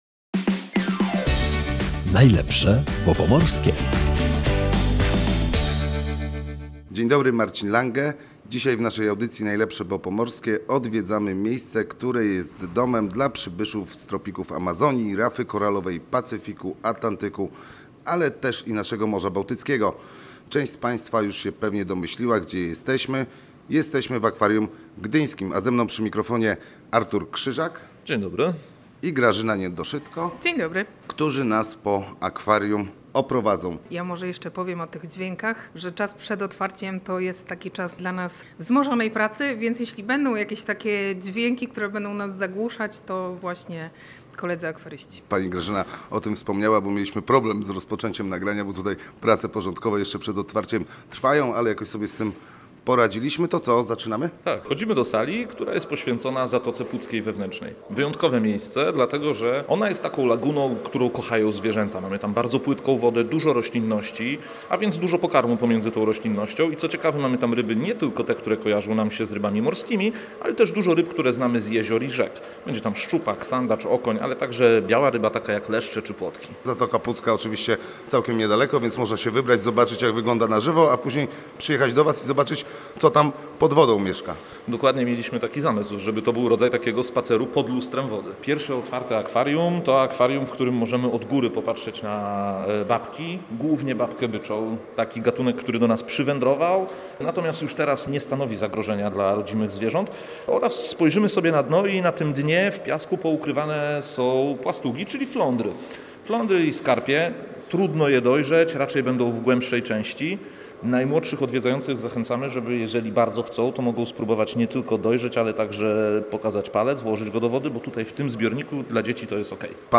W audycji „Najlepsze, bo pomorskie” odwiedzamy Akwarium Gdyńskie, które jest domem dla ponad 200 gatunków zwierząt – w tym także rekinów.